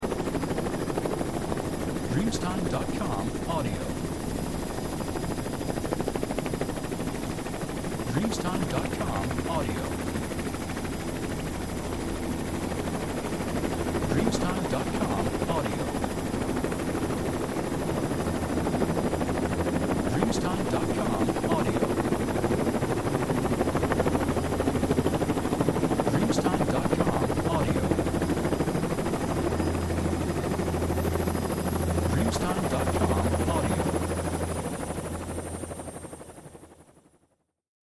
Hubschrauber 005